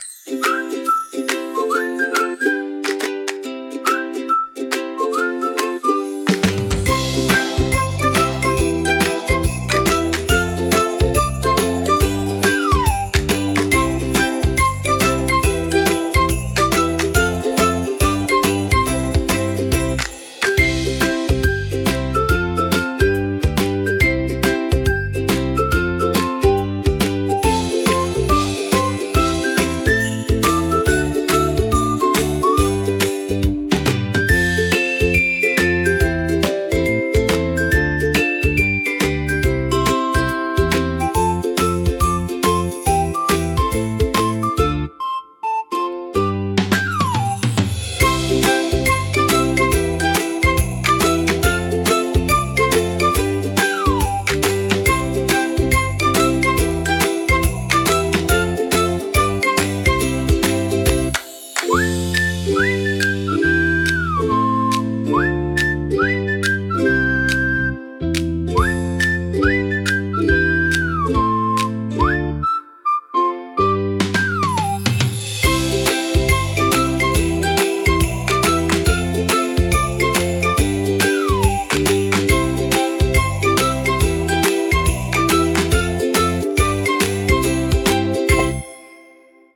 Sweet Kids Music